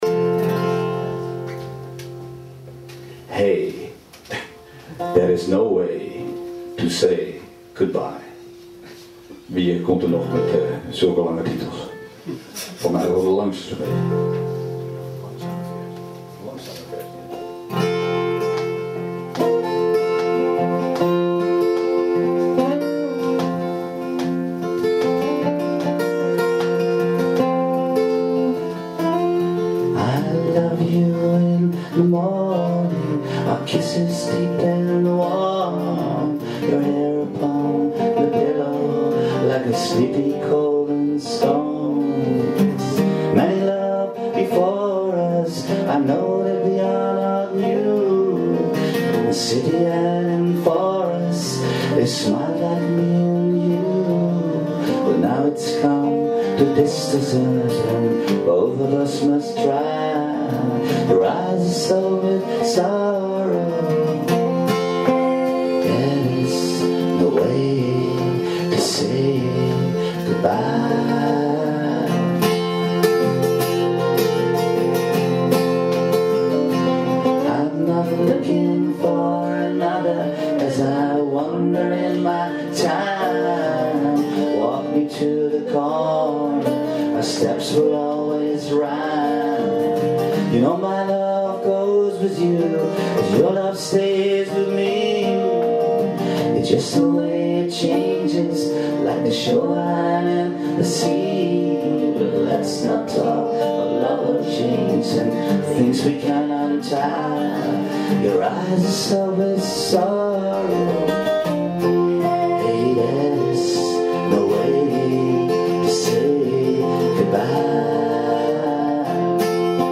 in the record shop Concerto, Amsterdam